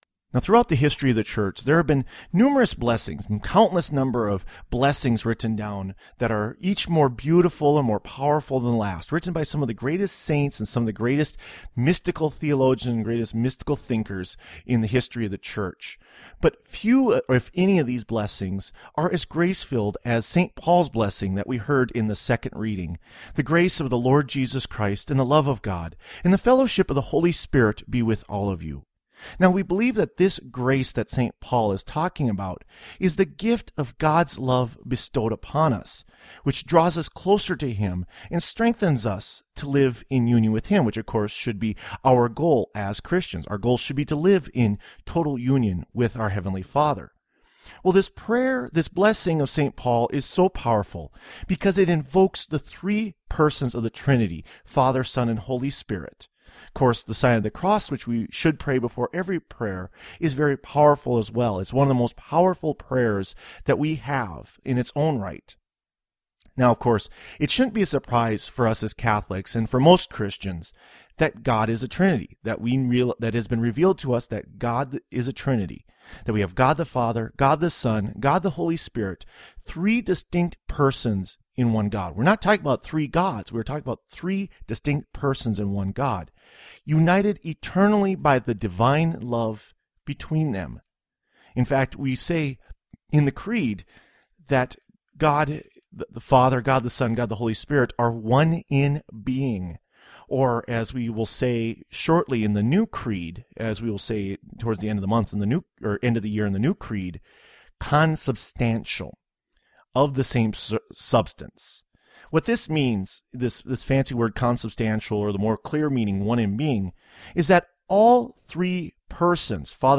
Homily for Holy Trinity Sunday